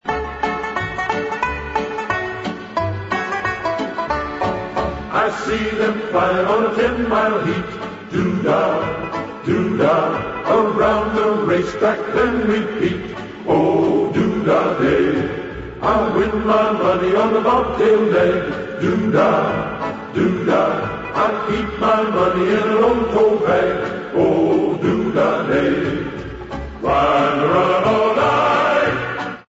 Ηχητική μπάντα παράστασης
sound track 02, διάρκεια 29'', τραγούδι